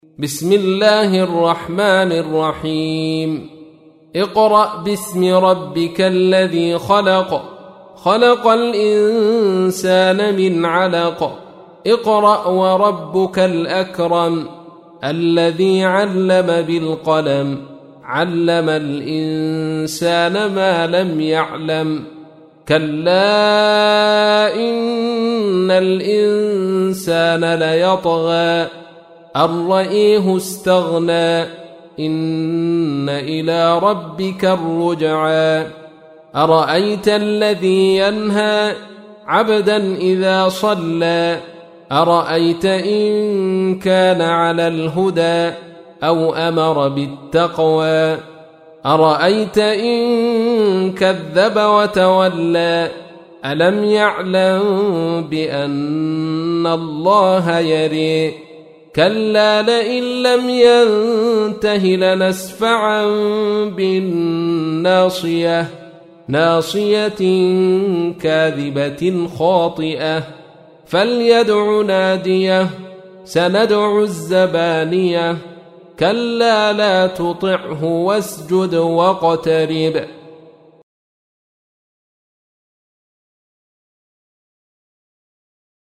تحميل : 96. سورة العلق / القارئ عبد الرشيد صوفي / القرآن الكريم / موقع يا حسين